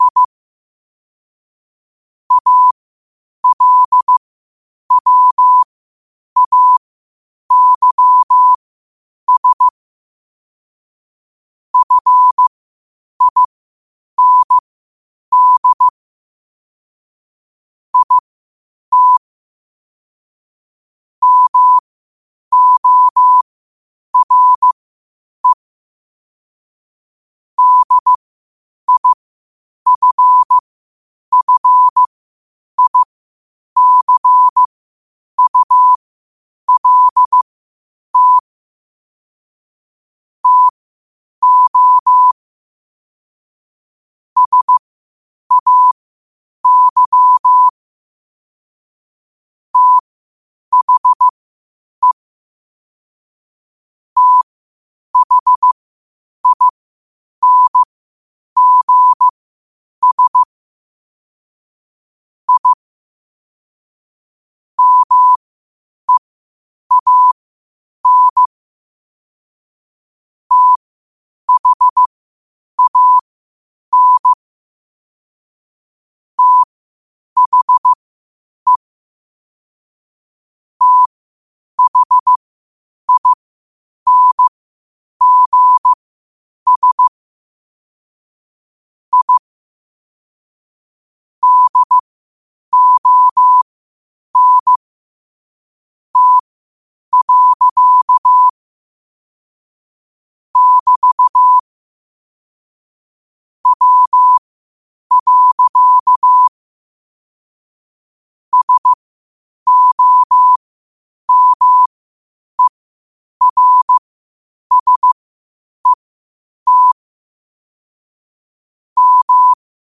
New quotes every day in morse code at 8 Words per minute.